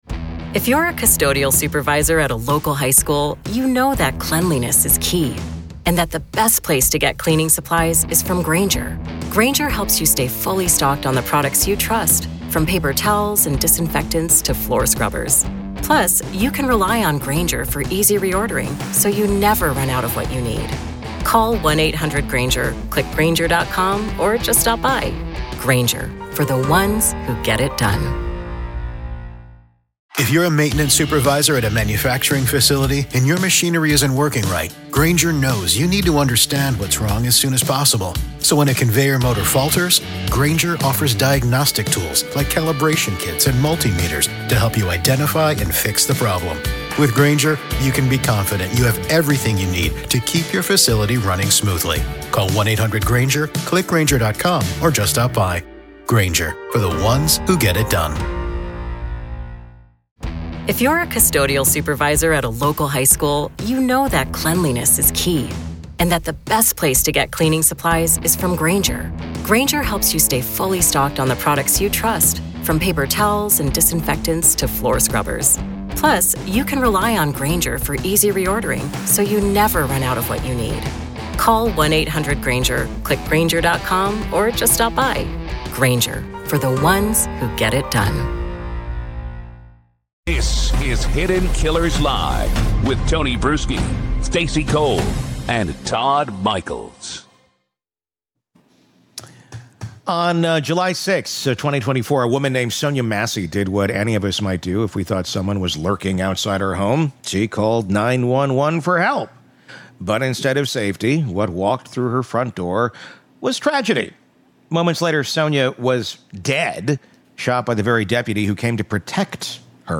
In this powerful conversation